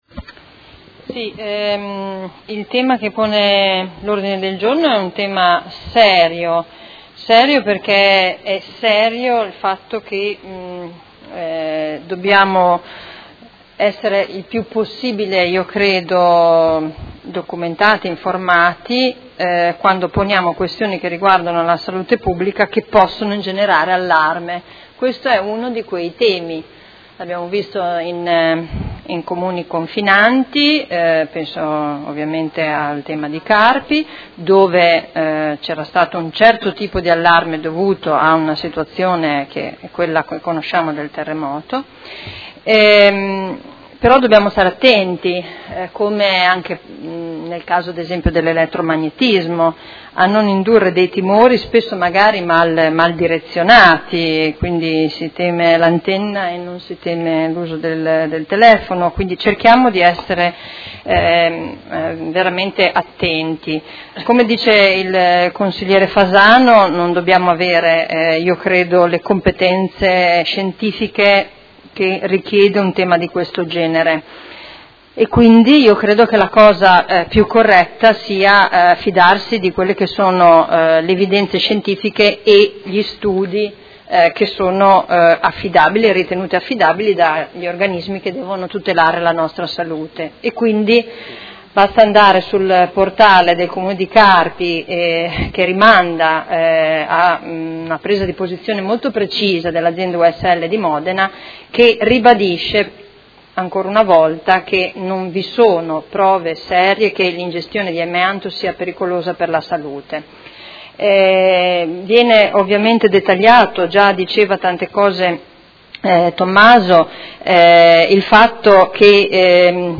Seduta del 27/10/2016 Dibattito su Mozione 106516 e Ordine de Giorno 157637